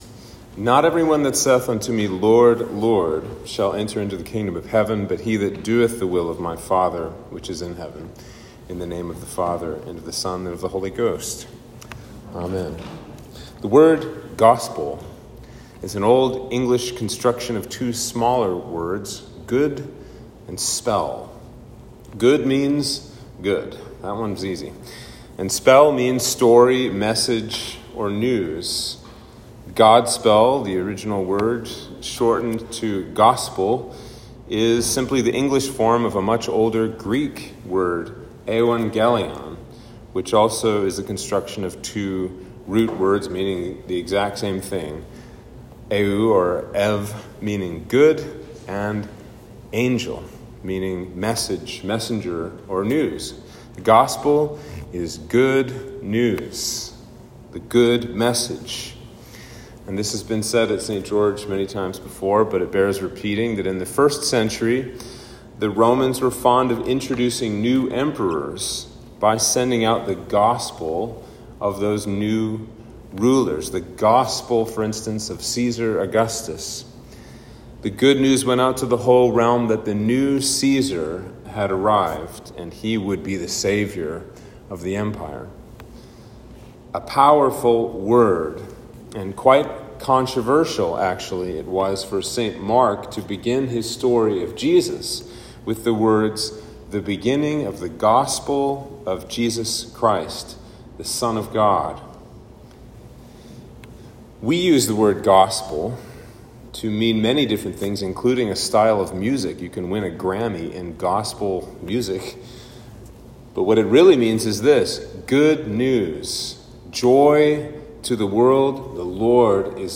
Sermon for Trinity 8